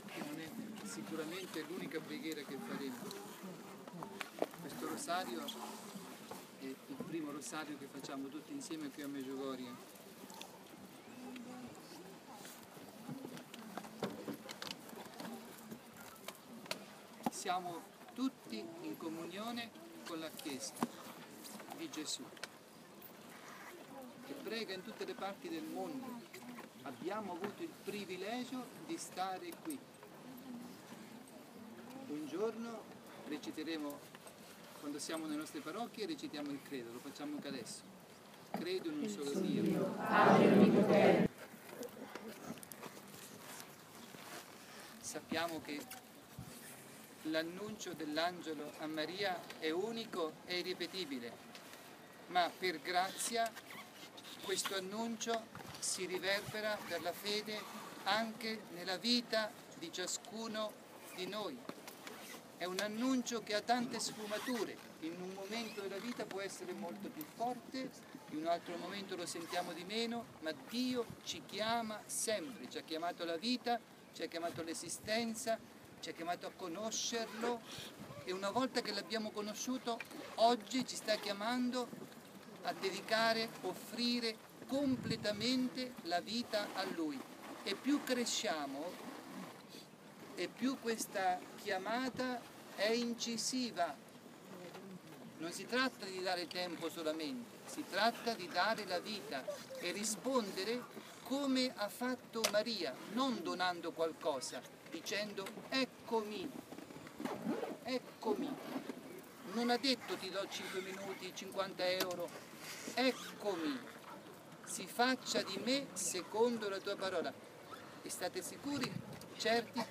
Pellegrinaggio a Medugorje del 15-20 maggio 2015
Sabato 16 maggio, ROSARIO  sulla collina delle apparizioni (Podbrdo)